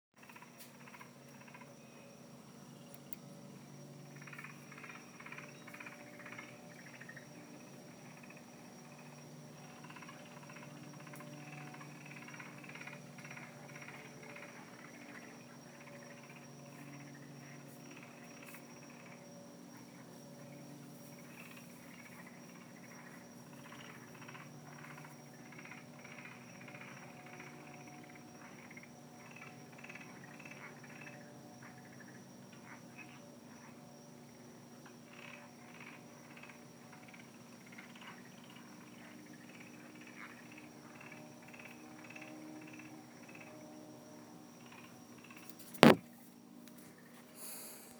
Frog Serenade
frogs.wav